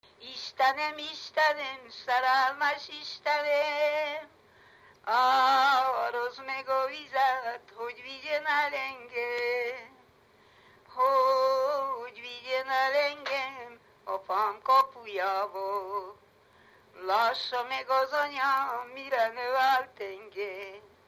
Erdély - Csík vm. - Rakottyástelep (Gyimesbükk)
ének
Műfaj: Keserves
Stílus: 7. Régies kisambitusú dallamok